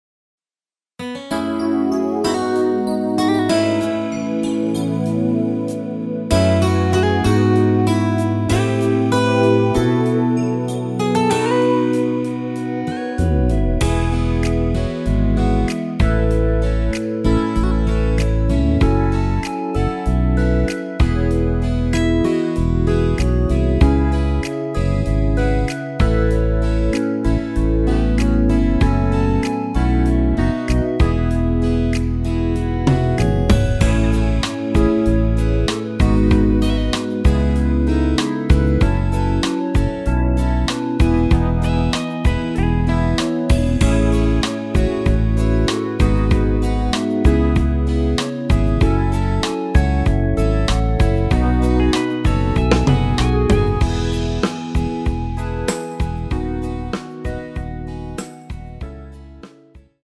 원키에서(+4)올린 멜로디 포함된 MR입니다.
앞부분30초, 뒷부분30초씩 편집해서 올려 드리고 있습니다.
중간에 음이 끈어지고 다시 나오는 이유는